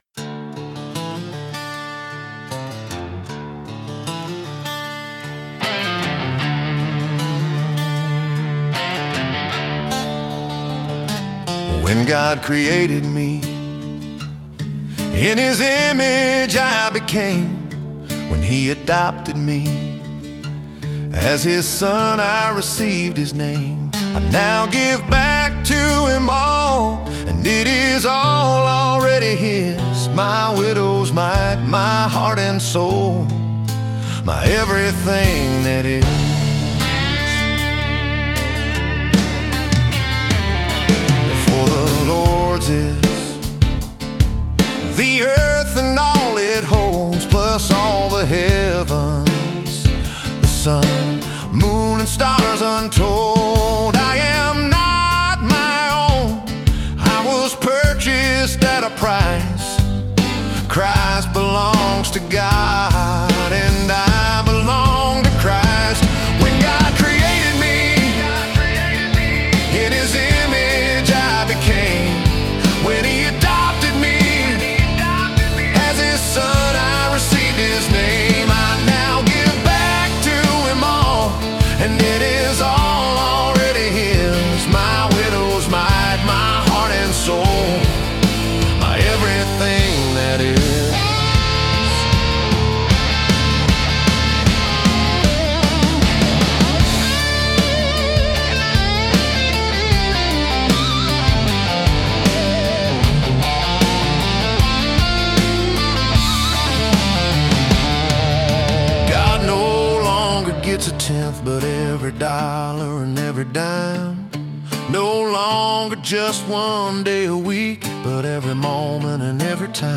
Blues Rock